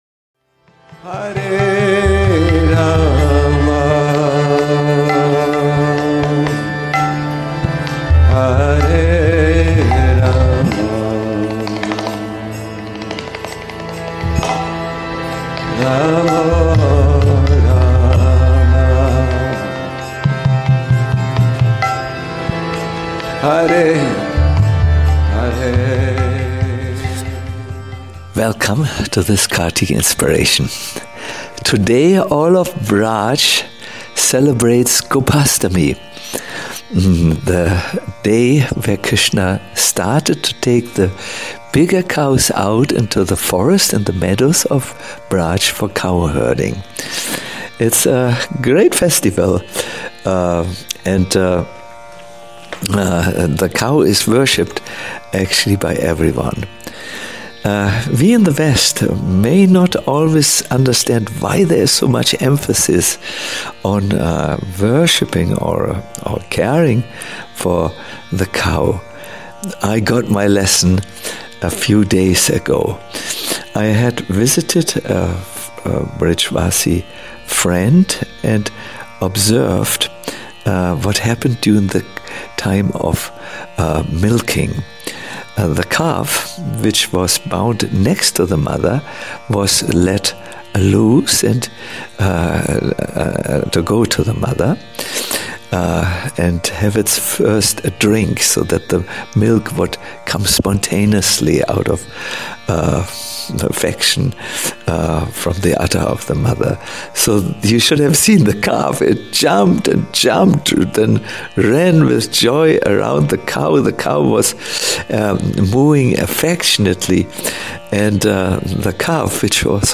Kartik Inspirations 24 - Cows - Oceans Of Affection - a lecture